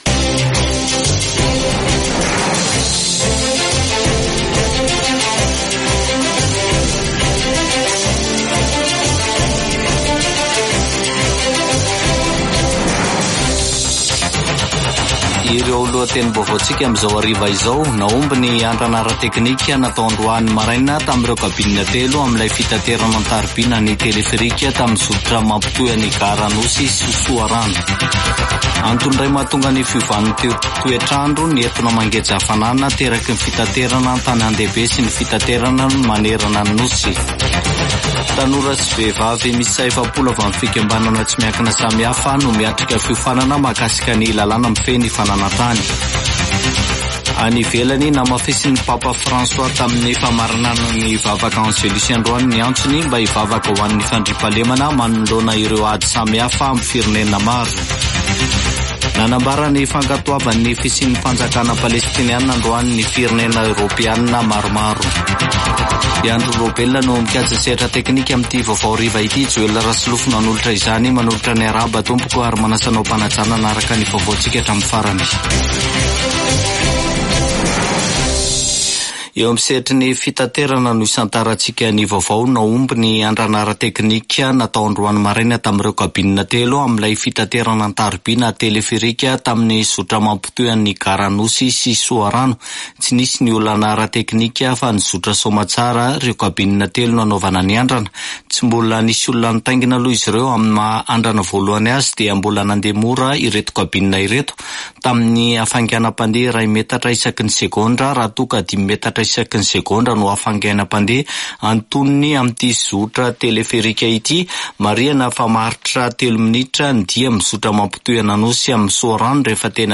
[Vaovao hariva] Alarobia 22 mey 2024